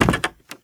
STEPS Wood, Creaky, Walk 26.wav